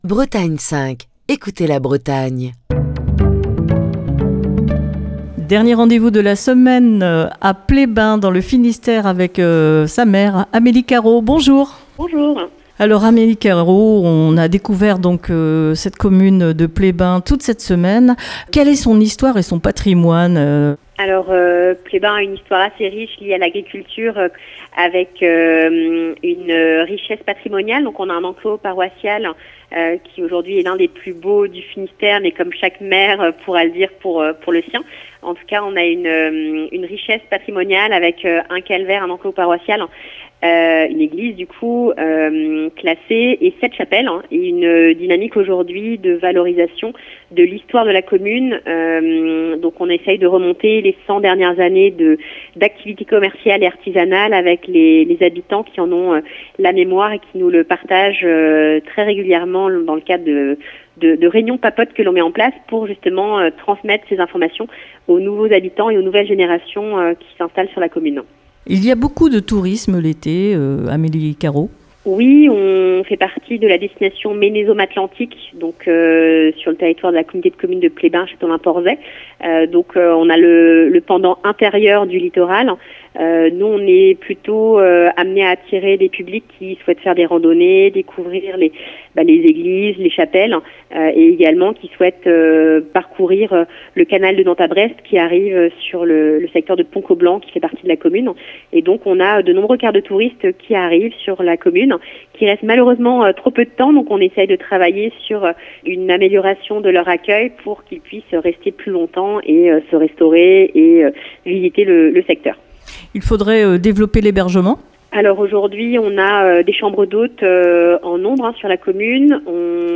Ce vendredi, dernier rendez-vous dans le Finistère à Pleyben où Destination commune a posé ses micros.